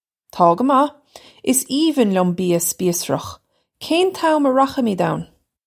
Pronunciation for how to say
Taw guh mah, is eevin lyum bee-ya spees-rukh. Kayn tam uh rakhamidge own?
This is an approximate phonetic pronunciation of the phrase.